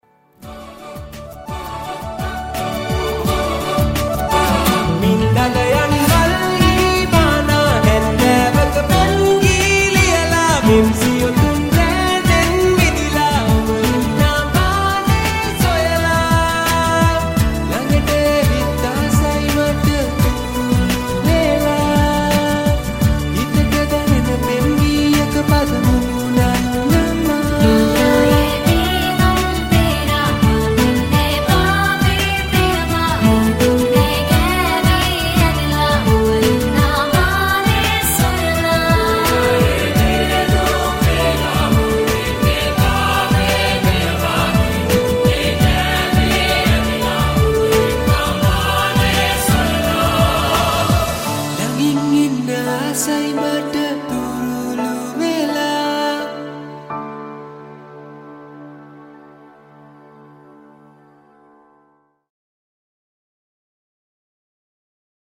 Vocals
Backup Choir Vocals
Acoustic Guitar